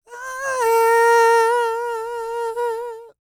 E-CROON 3029.wav